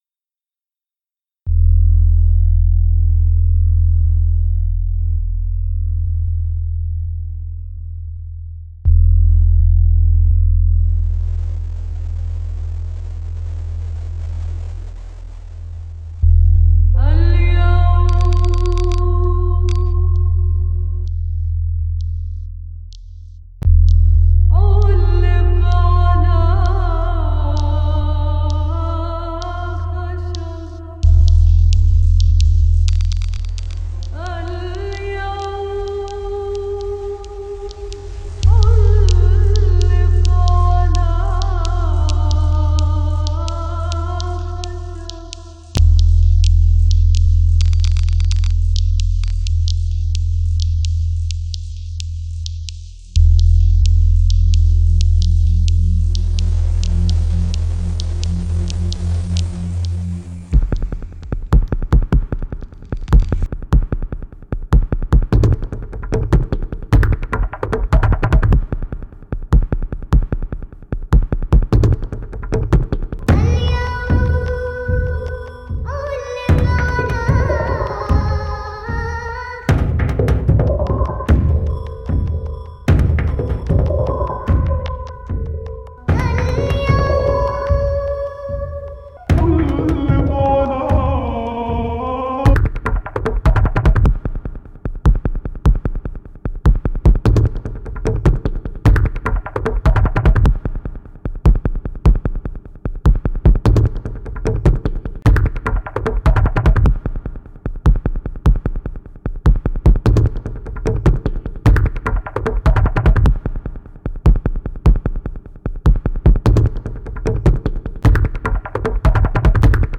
3_1 – audio techno oriental (audio):
3_1 - audio techno oriental.mp3